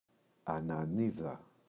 ανανίδα, η [ana’niða]